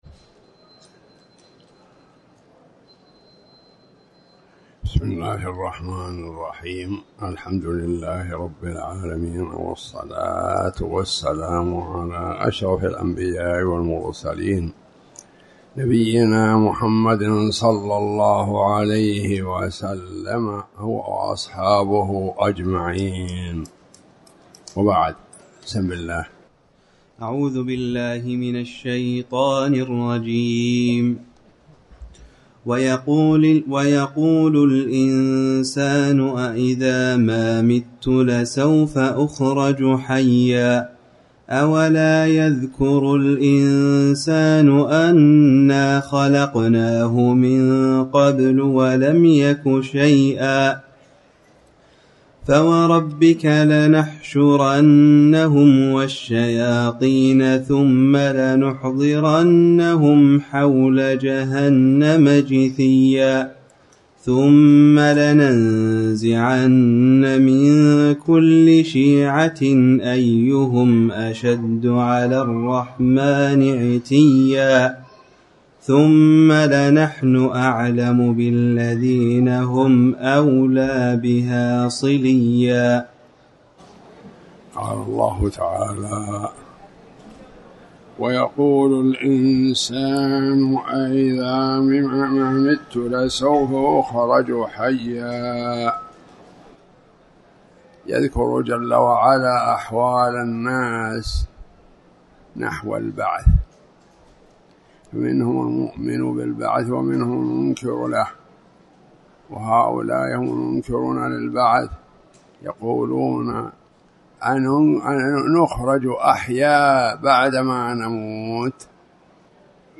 تاريخ النشر ١٣ شوال ١٤٣٩ هـ المكان: المسجد الحرام الشيخ